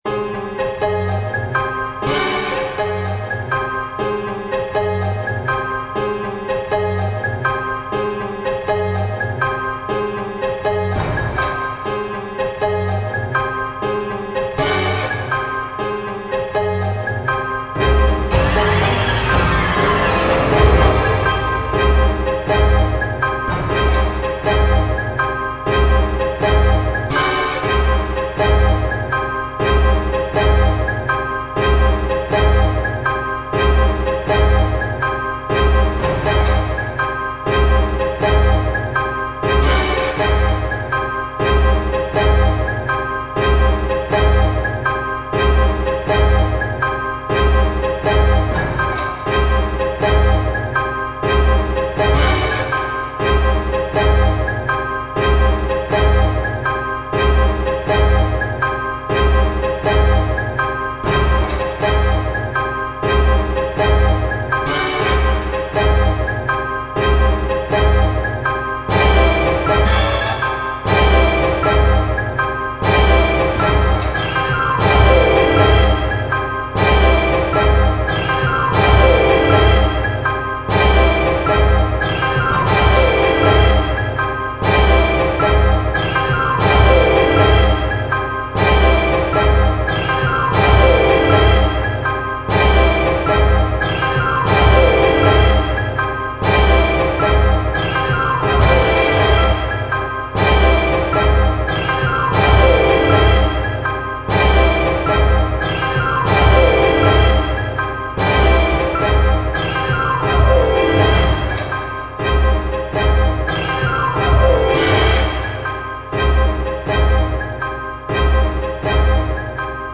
Danza y Mùsica Electrònica.
El trabajo escénico de los bailarines esta enmarcado y tiene como fondo la música electrónica en vivo, generada como una alegoría a un (inexistente) bandoneón virtual.